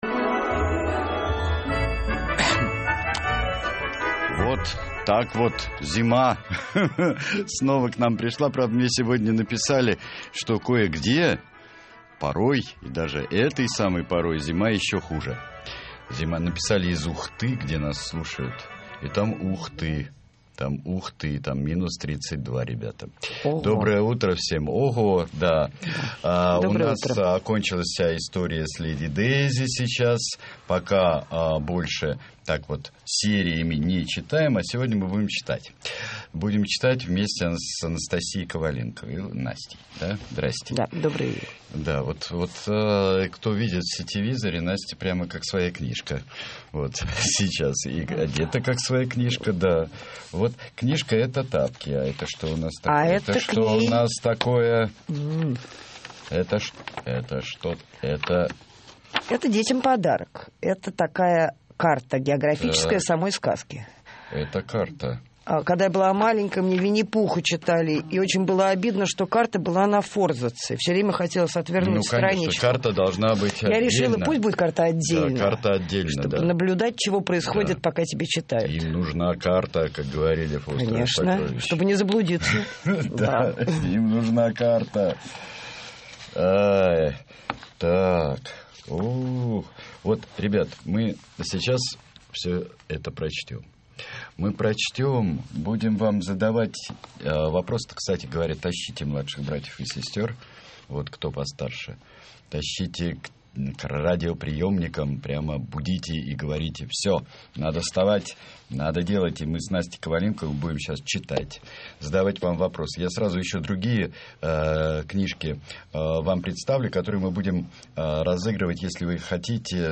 ЗВУЧИТ ФРАГМЕНТ ИЗ КНИГИ "ТАПКИ"